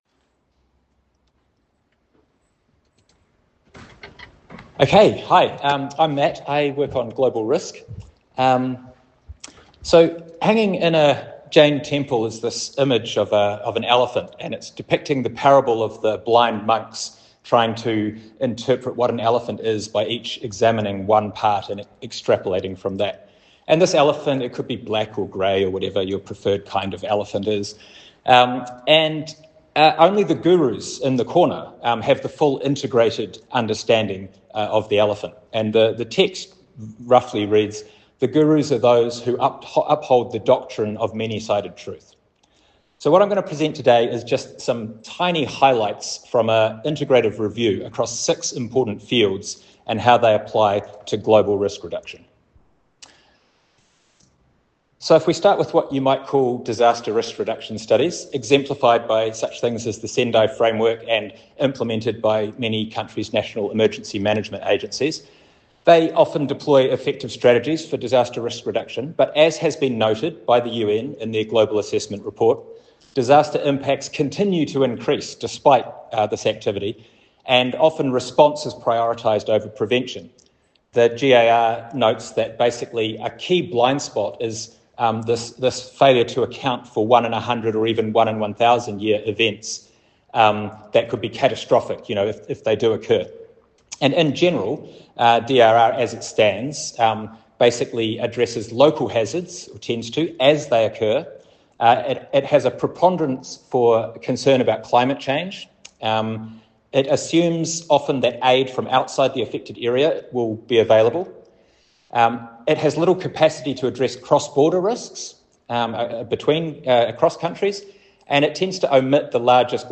My Society for Risk Analysis Conference Presentation
At the Australia New Zealand Society for Risk Analysis Conference in Christchurch on 29-30 Jan 2026 it was clear that systemic risk and polycrisis concepts are moving from the margins to the centre of risk analysis.